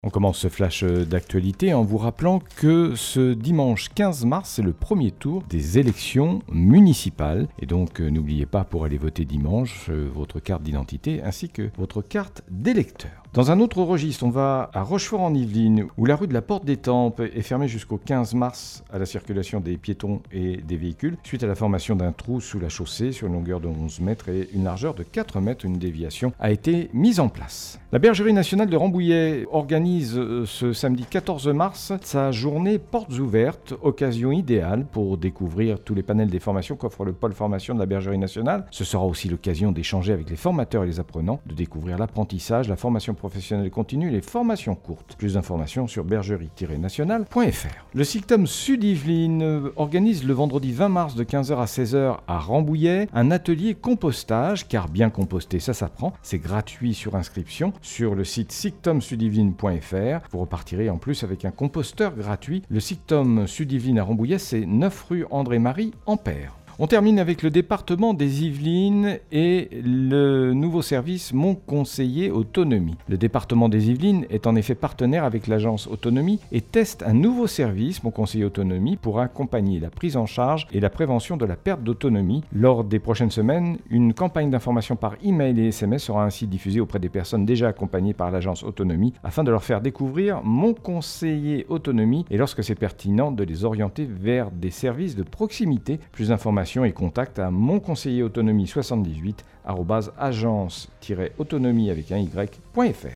09.03-FLASH-LOCAL-MATIN.mp3